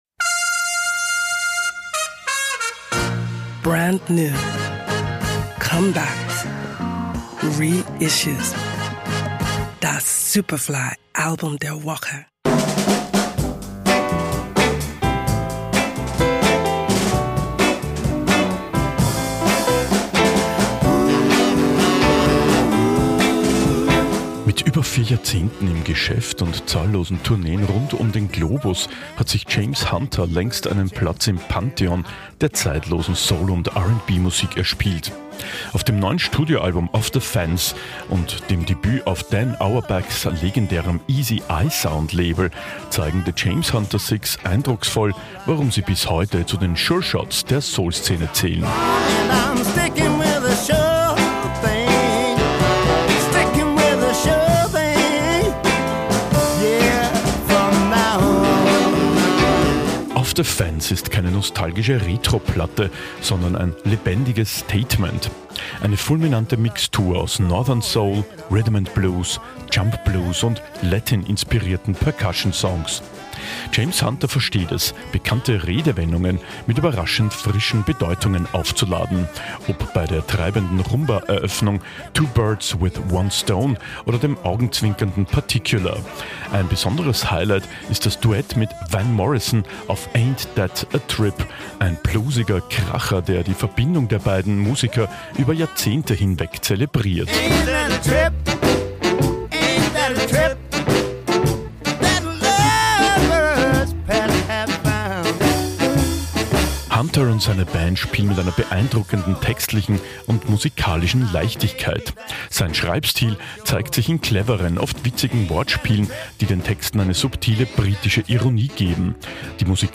Soul- und R&B-Musik